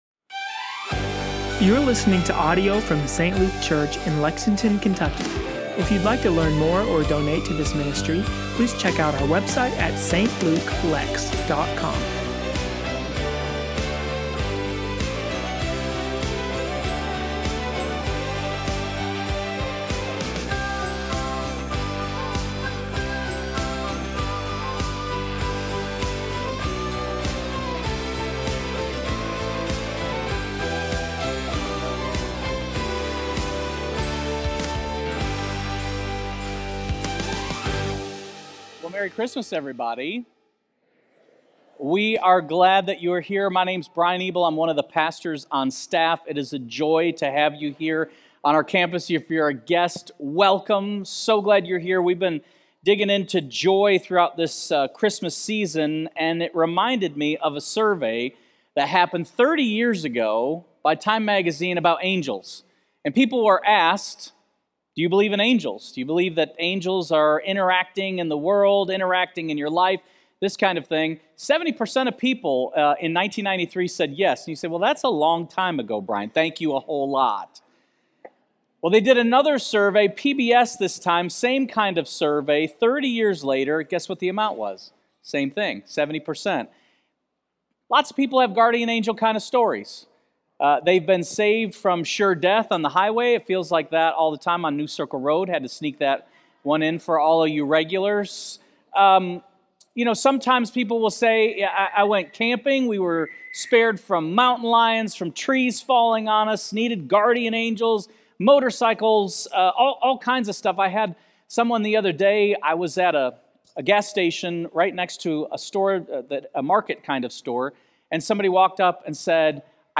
Sermons & Teachings